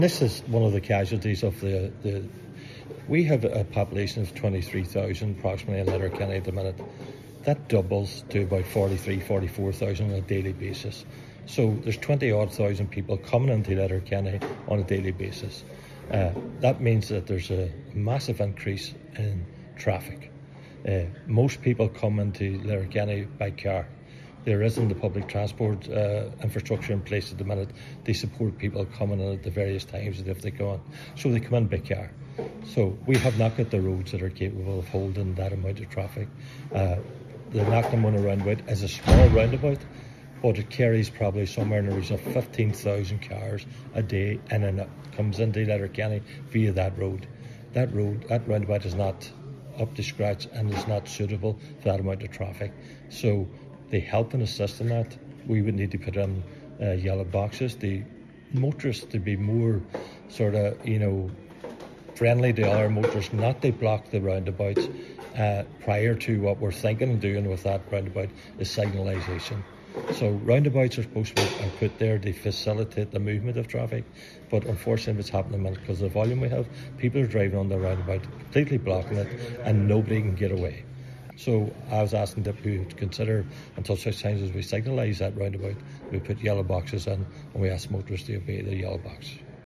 Councillor McMonagle, however, says interim measures are needed: